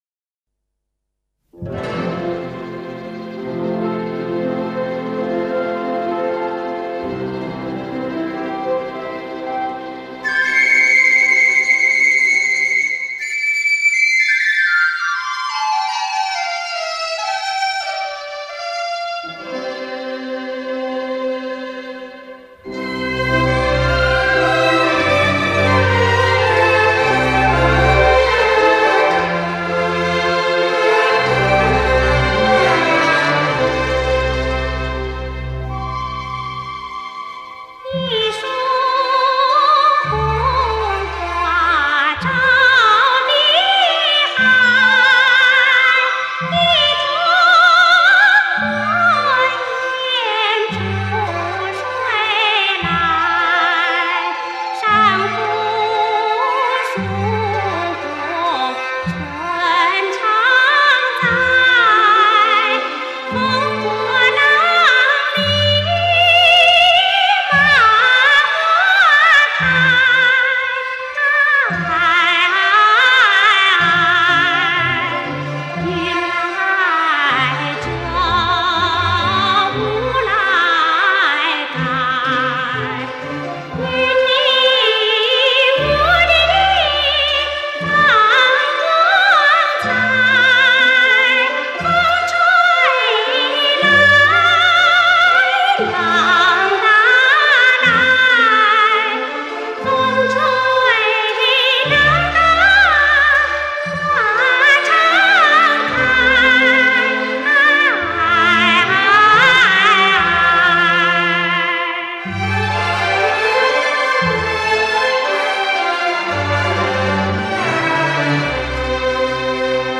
中国民族歌剧珍藏版